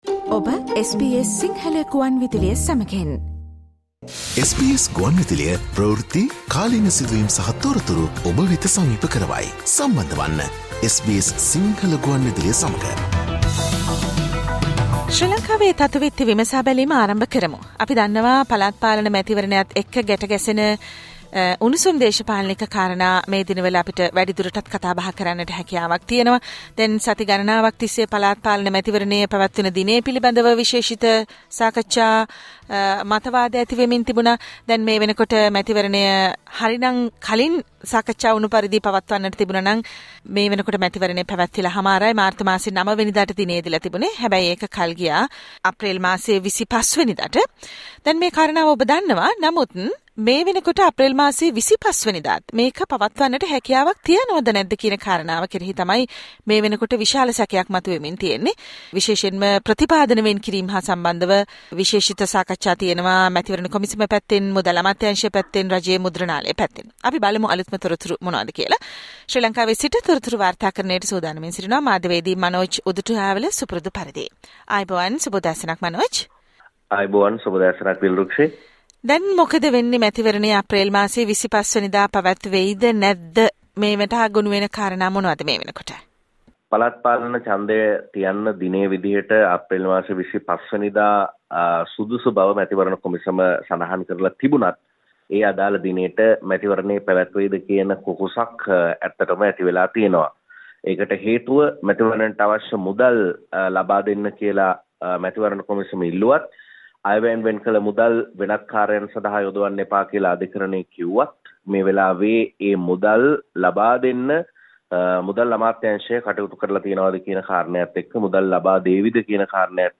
SBS Sinhala radio brings you the most prominent political news highlights of Sri Lanka in this featured Radio update on every Monday.